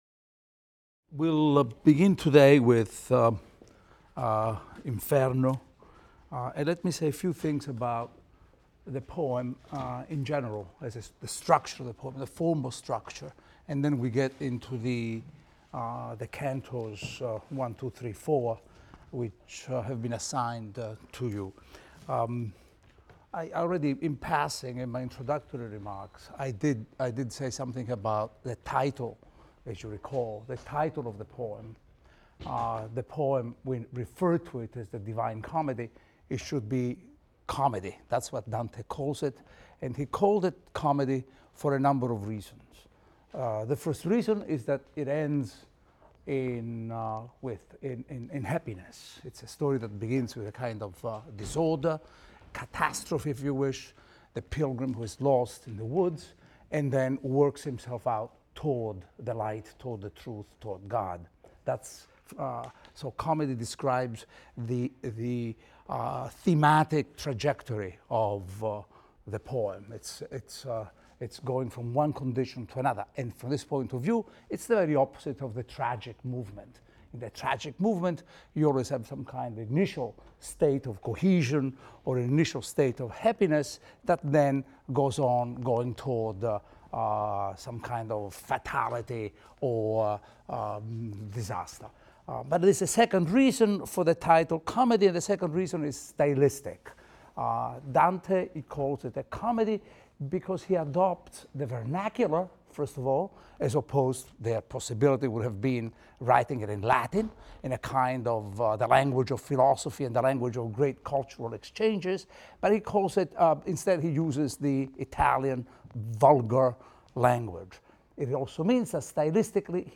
ITAL 310 - Lecture 3 - Inferno I, II, III, IV | Open Yale Courses